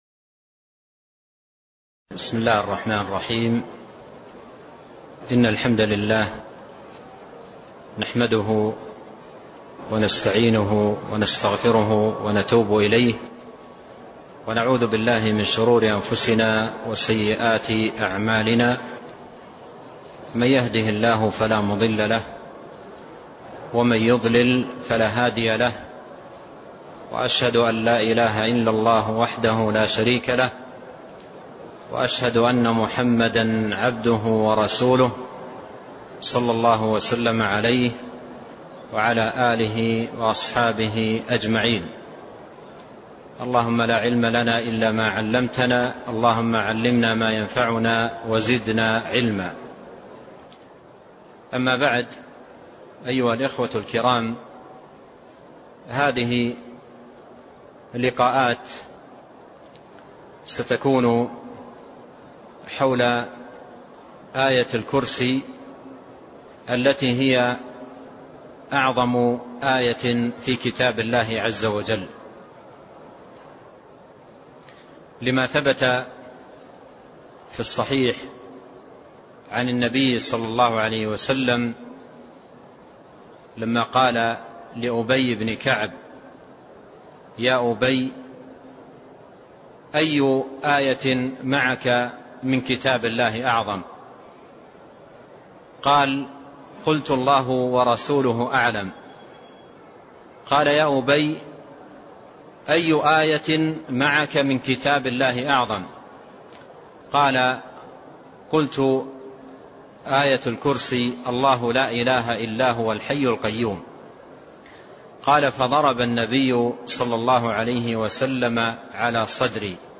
تاريخ النشر ٢٣ جمادى الأولى ١٤٢٧ هـ المكان: المسجد النبوي الشيخ